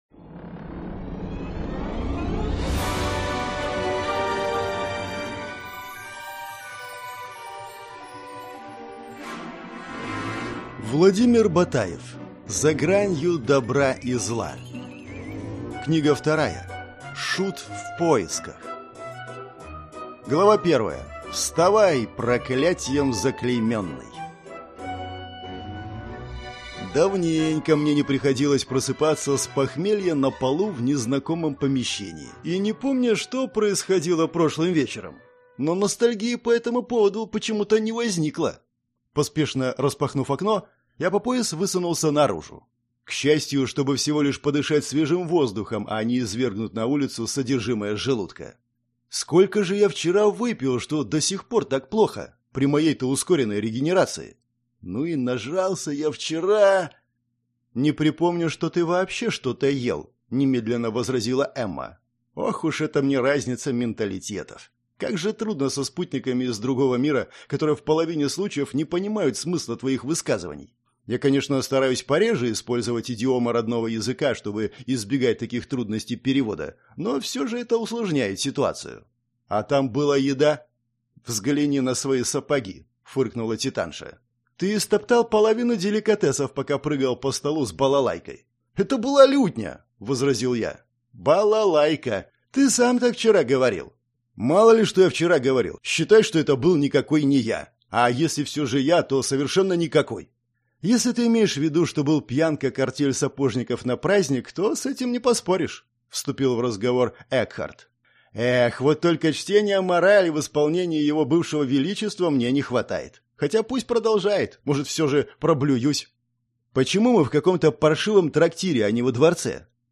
Аудиокнига За гранью добра и зла. Книга 2. Шут в поисках | Библиотека аудиокниг